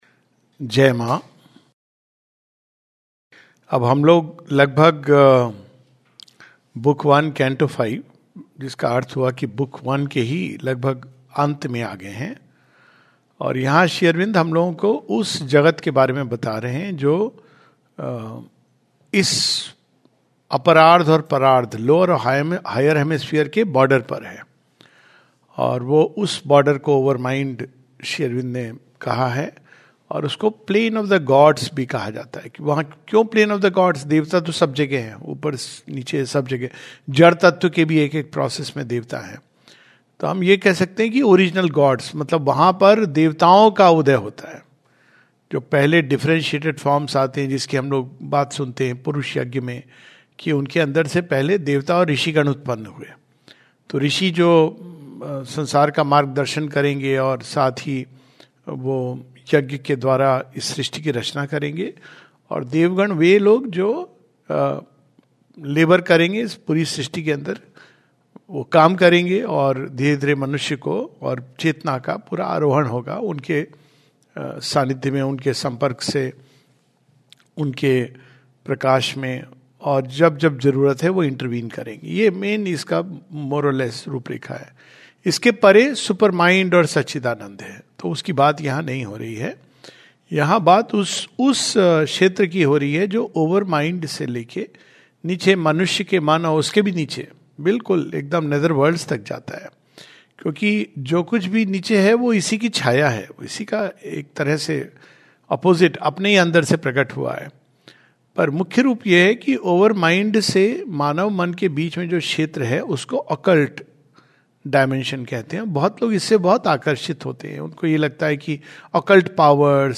Savitri study class
The passages on pages 87-89 were read today.